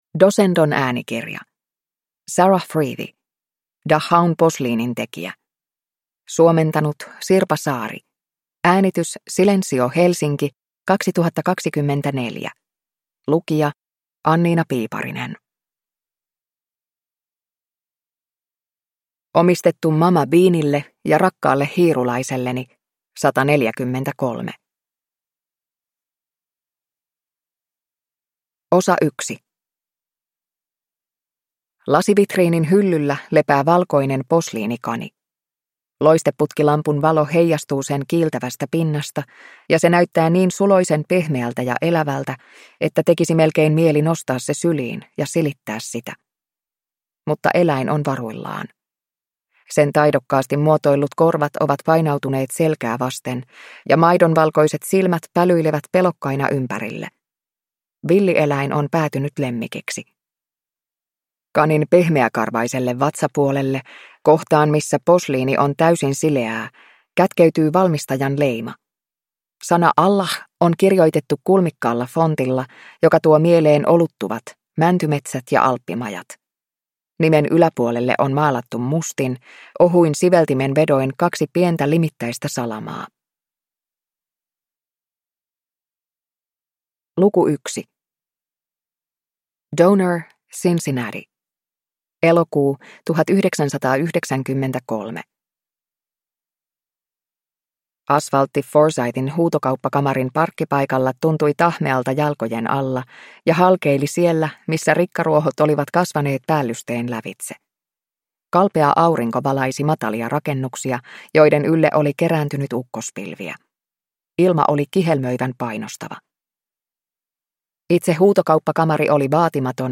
Dachaun posliinintekijä – Ljudbok